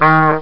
Hornbulb Sound Effect
hornbulb.mp3